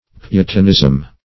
Search Result for " putanism" : The Collaborative International Dictionary of English v.0.48: Putanism \Pu"tan*ism\, n. [F. putanisme, fr. putain harlot.]